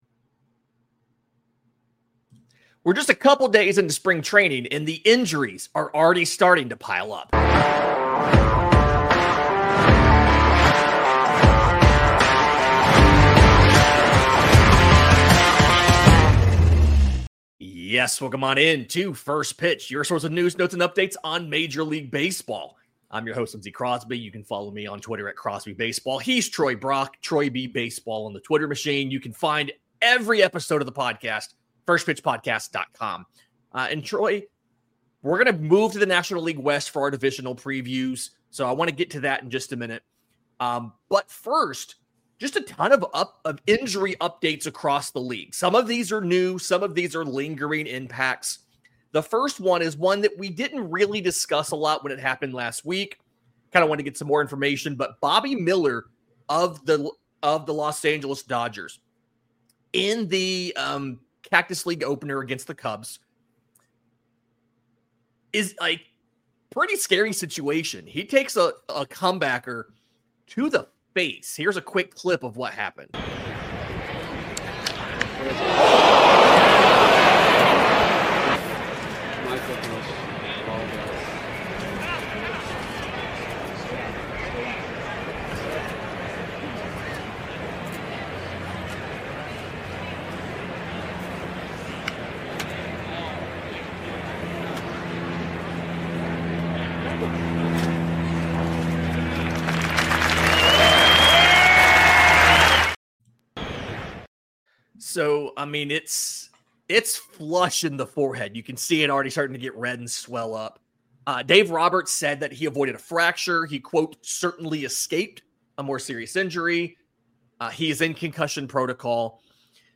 The boys are back to discuss the injuries that are already beginning to add up.